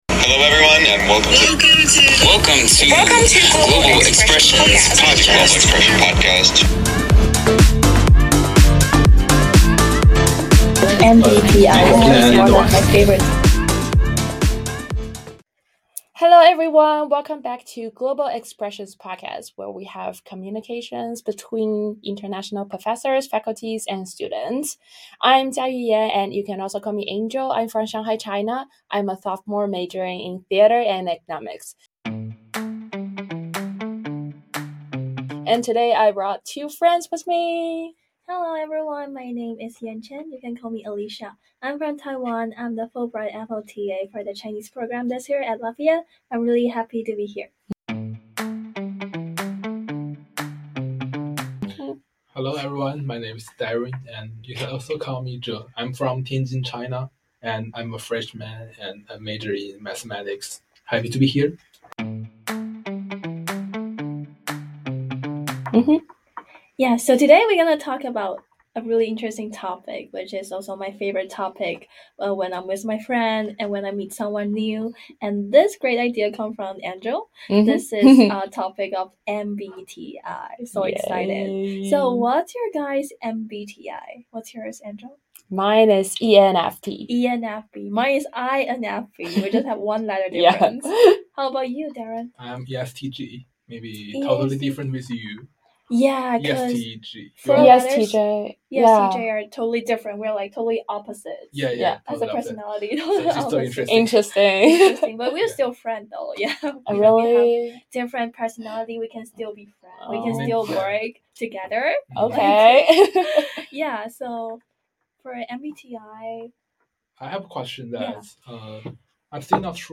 Peer Conversations